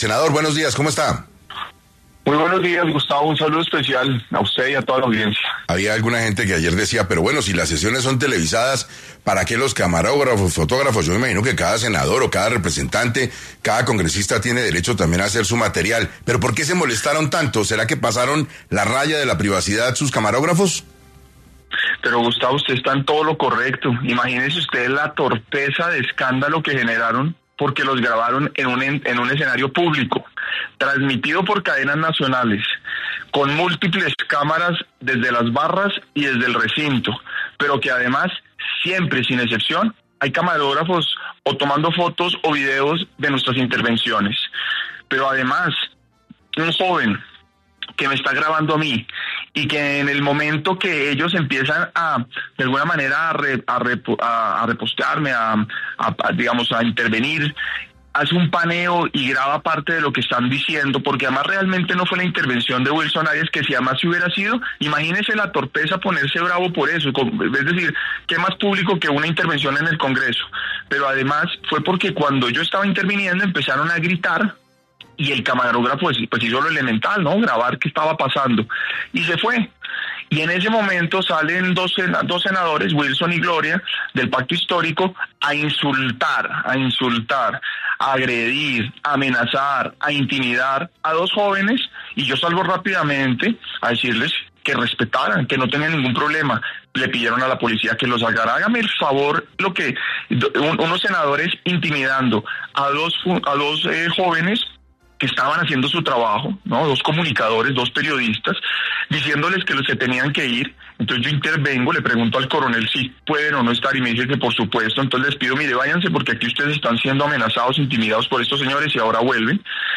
En 6AM de Caracol Radio estuvo el senador Miguel Uribe, para hablar sobre la acalorada discusión que se sostuvo en la plenaria del Senado el pasado miércoles 26 de febrero y qué responde ante las acusaciones.